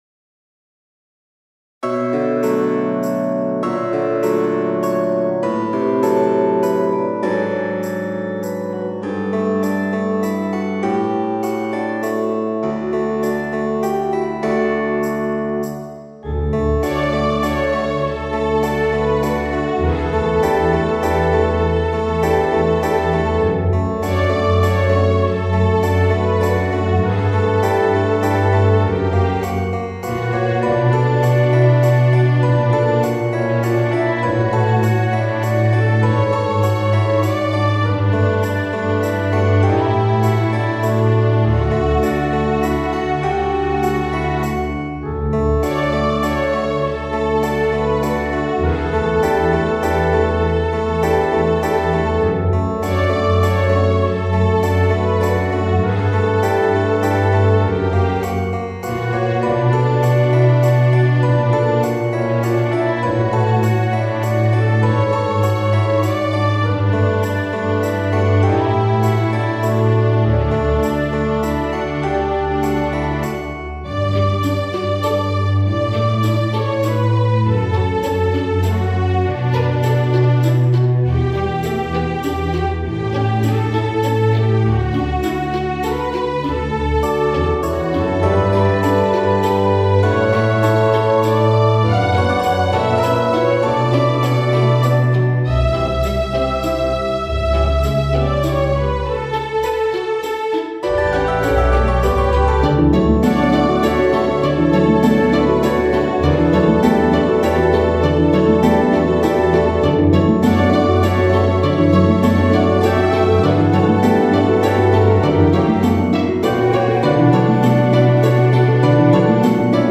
クラシックスローテンポロング